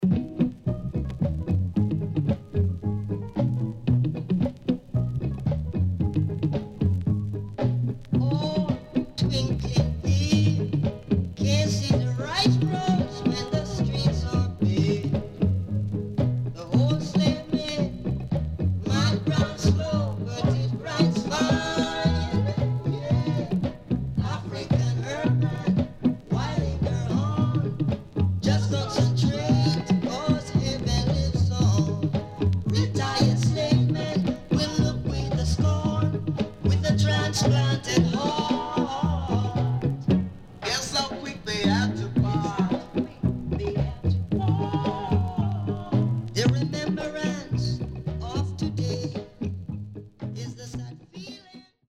SIDE A:全体的にプレス起因のチリノイズ入ります。